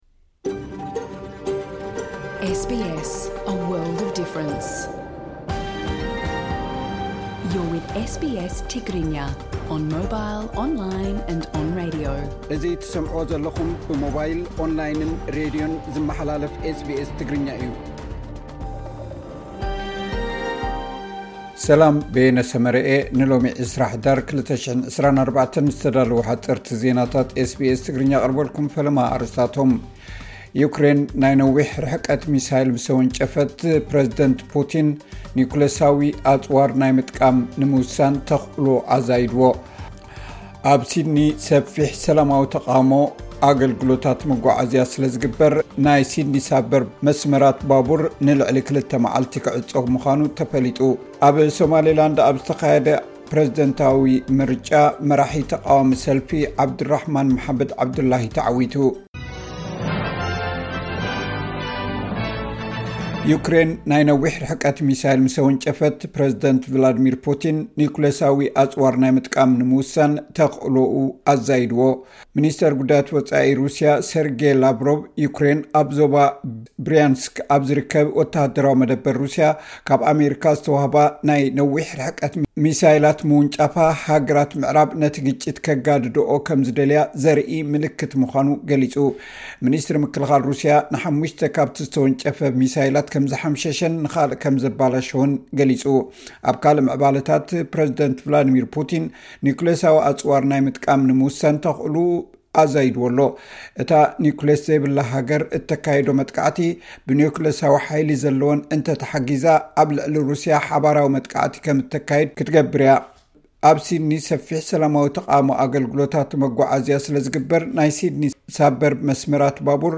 ሓጸርቲ ዜናታት ኤስ ቢ ኤስ ትግርኛ (20 ሕዳር 2024)